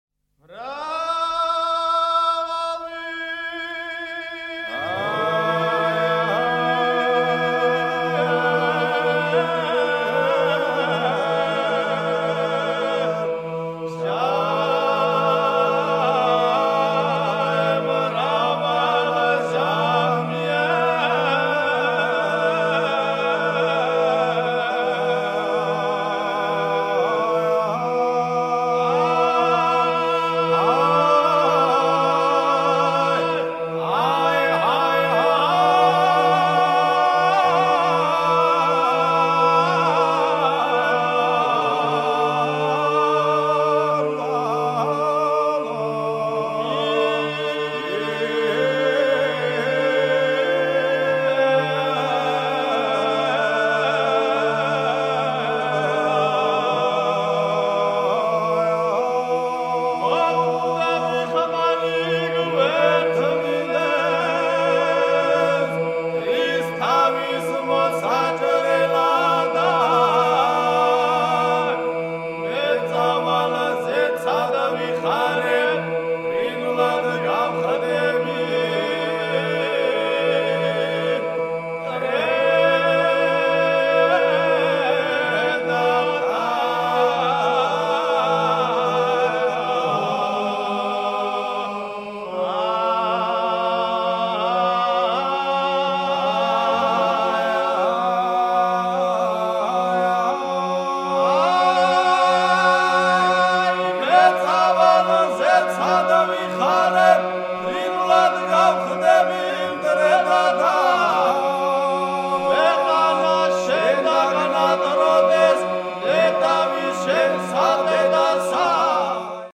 performed here with passion and invention